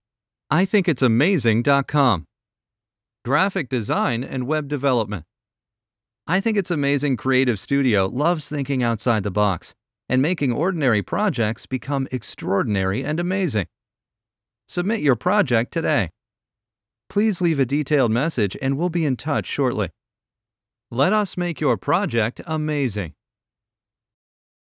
Male
My voice has been described as honest, warm, soothing, articulate, relatable, sincere, natural, conversational, friendly, powerful, intelligent and "the guy next door."
Phone Greetings / On Hold